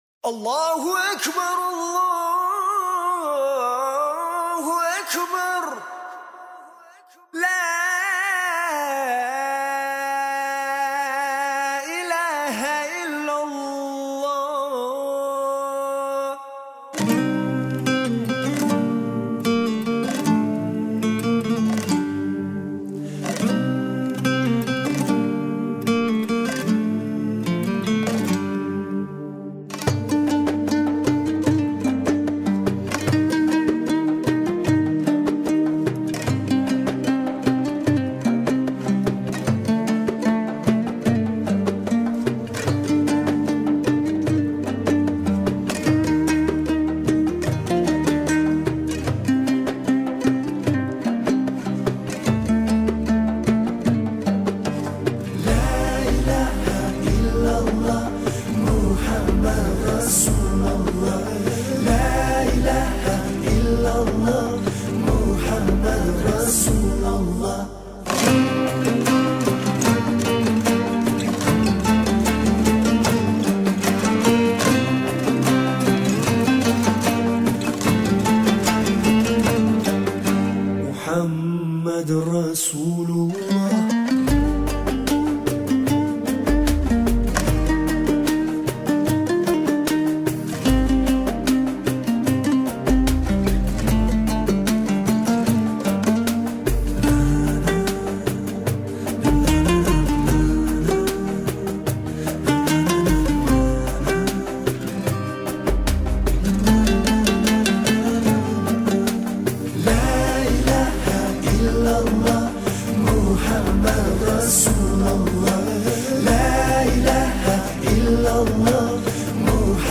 казахской поп-музыки